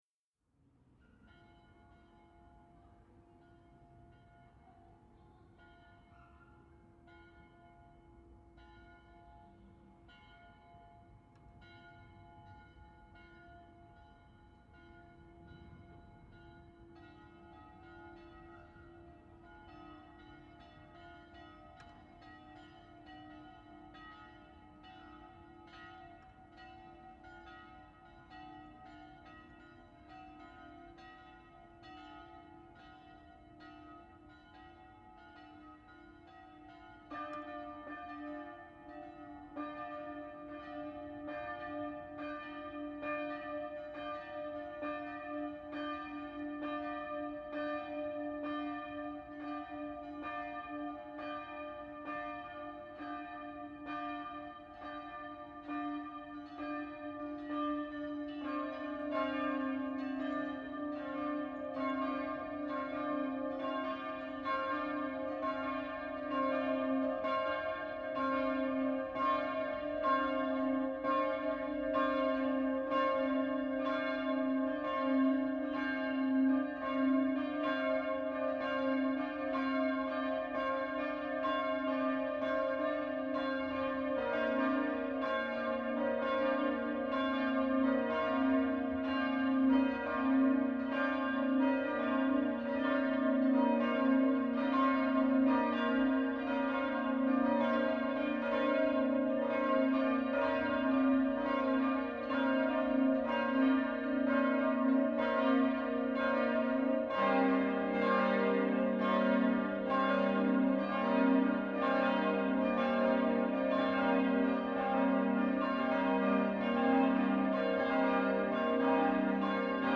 Die Glocken der Werler Wallfahrtskirche
01-Glockengelaeut.mp3